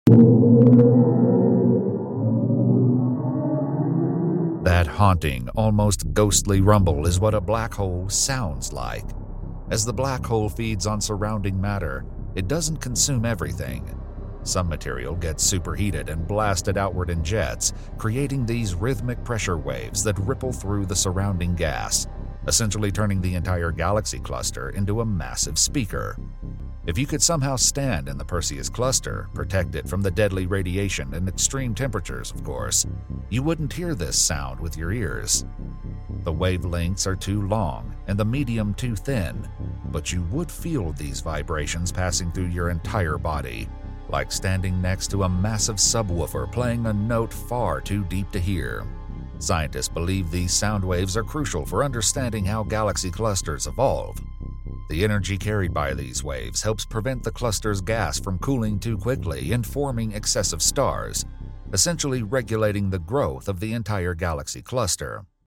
What the Perseus Cluster sounds sound effects free download
What the Perseus Cluster sounds like.